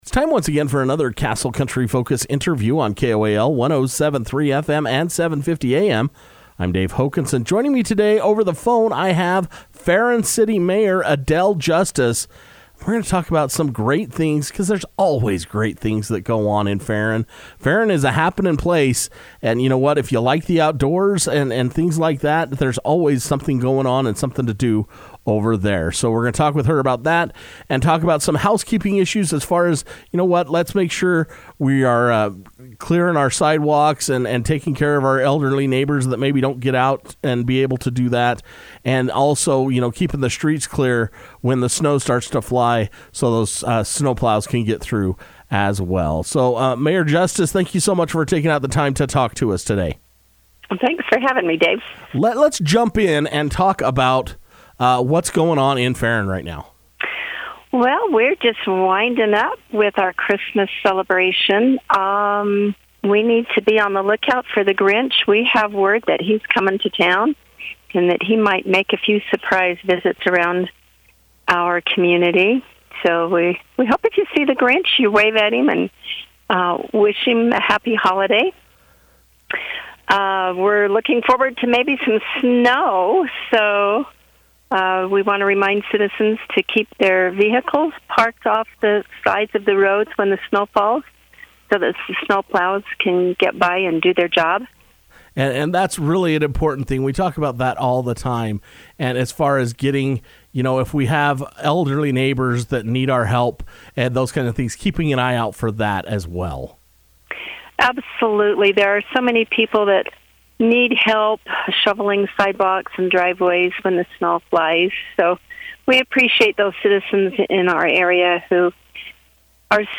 Castle Country Radio was able to speak over the telephone with Ferron City Mayor Adele Justice to talk about the Grinch visiting the city and snow removal.